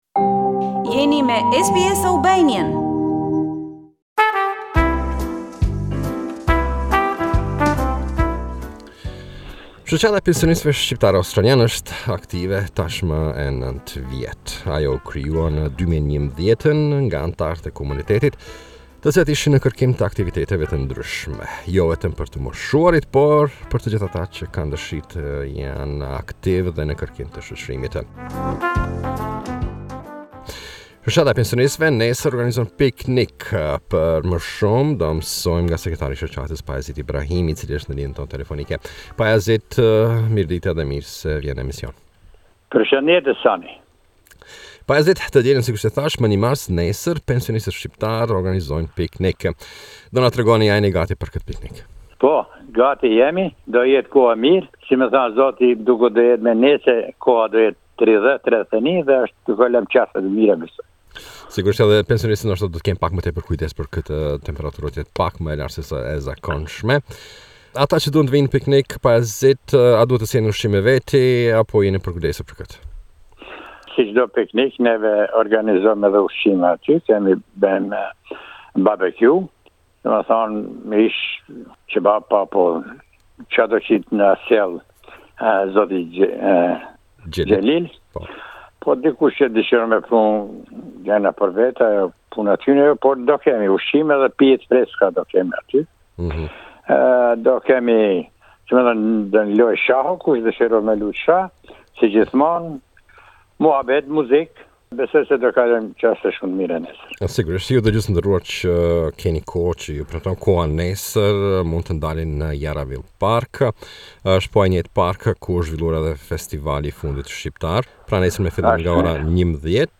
te cilit i morrëm një intervistë.